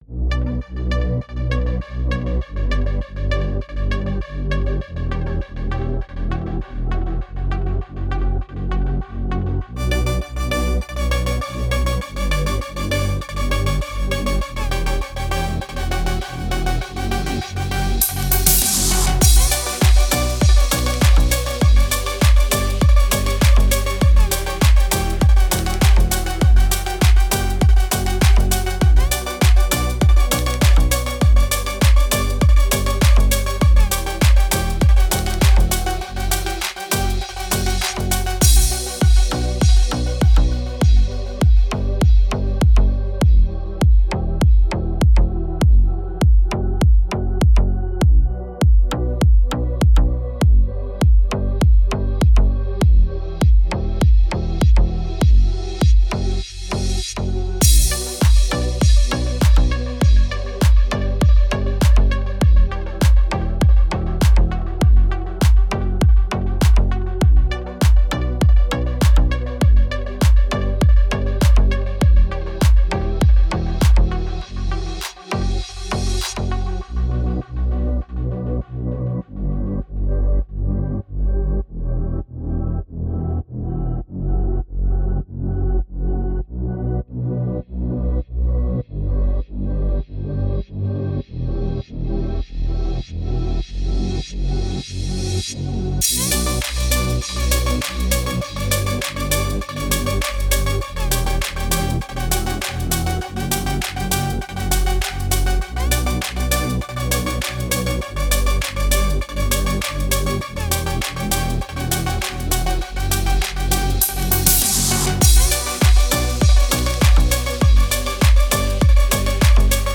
Отличные треки 2023 без слов